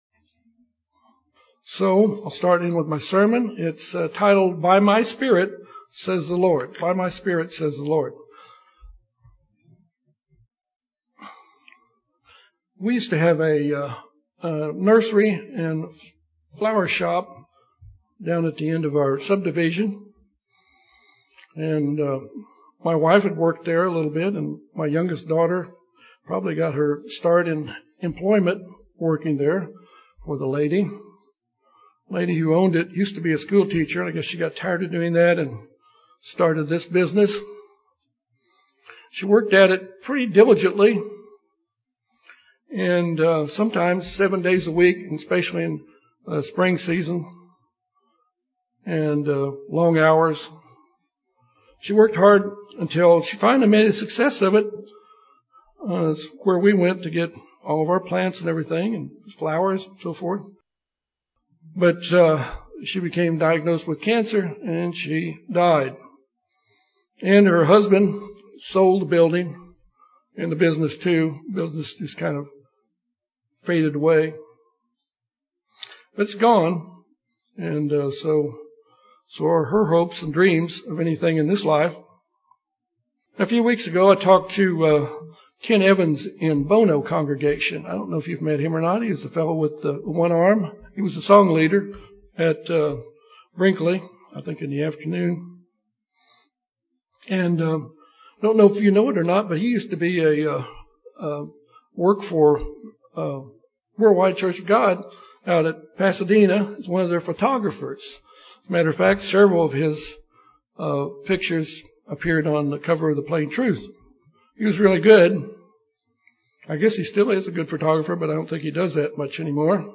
This sermon examines important events in the lives of Gideon and Peter.